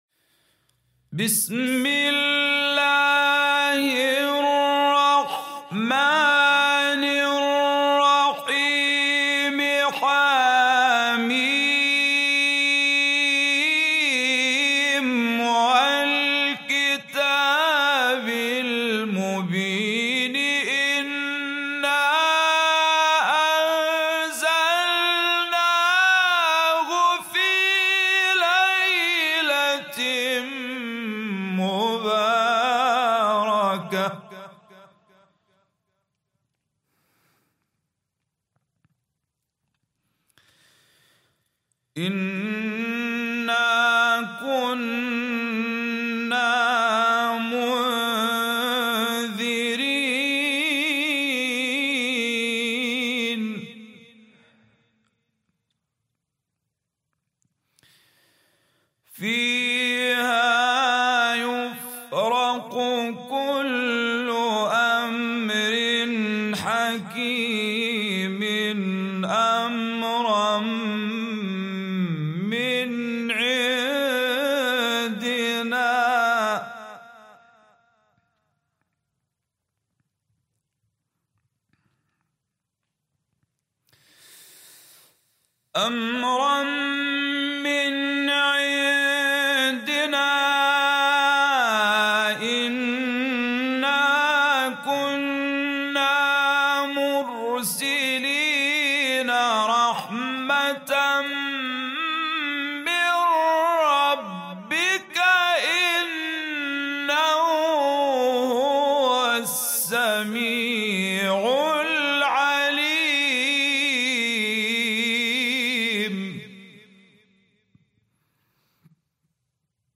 An Epic Quran recitation to welcome Laylat-Al Qadr.mp3